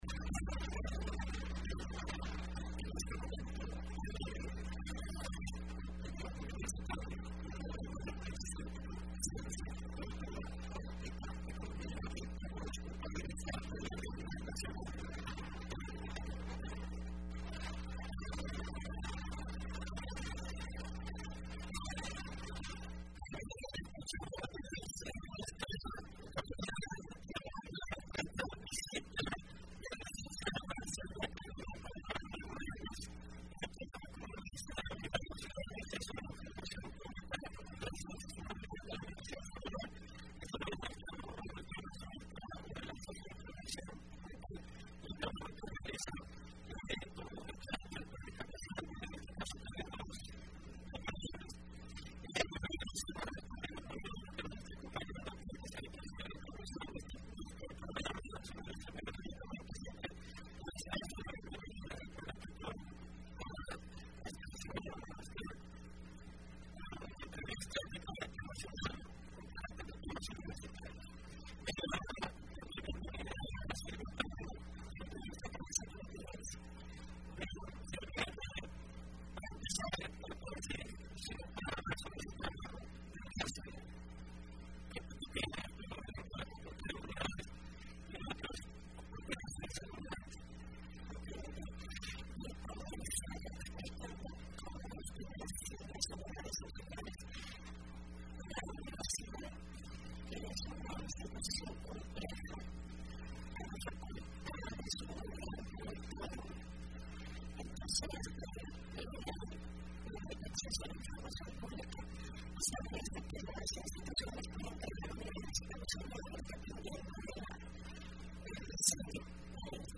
Entrevista Opinión Universitaria (26 mayo 2015) : Trabajo de la oficina de enlace de la Unidad de Acceso a la información Pública